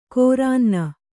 ♪ kōrānna